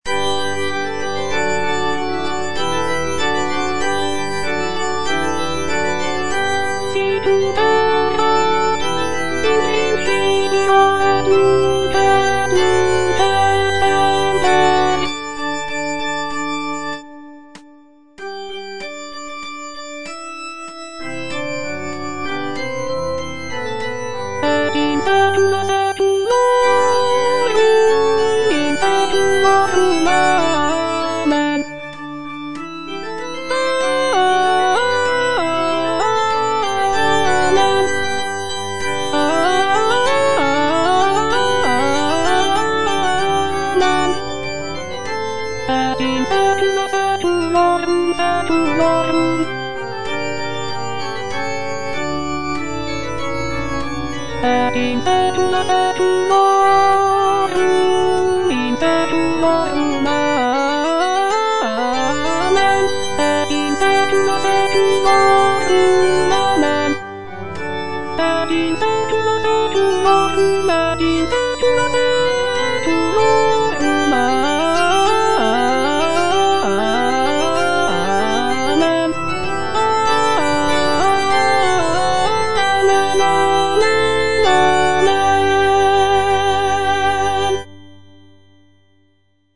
B. GALUPPI - MAGNIFICAT Sicut erat in principio - Alto (Voice with metronome) Ads stop: auto-stop
The work features intricate vocal lines, rich harmonies, and dynamic contrasts, creating a powerful and moving musical experience for both performers and listeners.